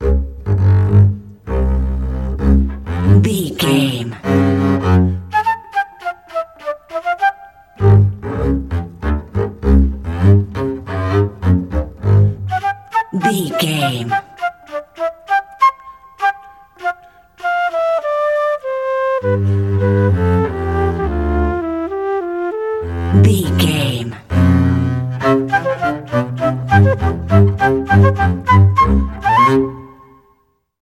Ionian/Major
fun
playful
joyful
double bass
flute
classical duet